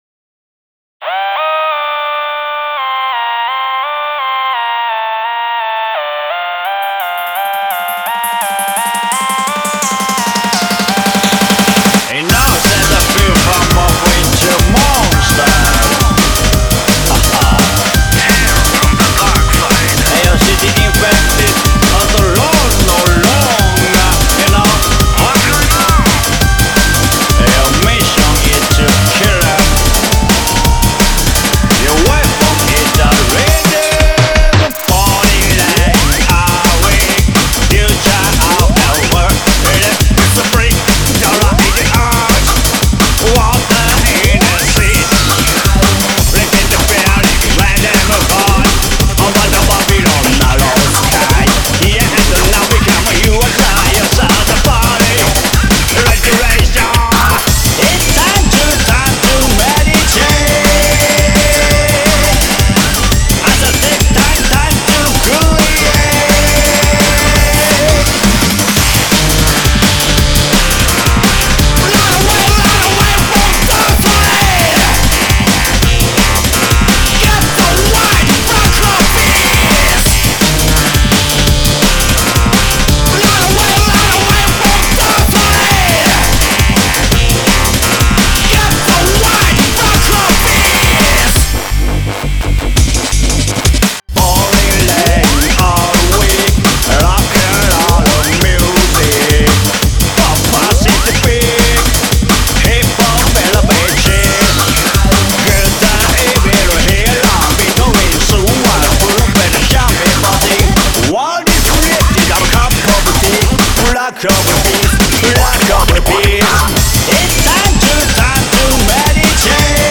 J-Core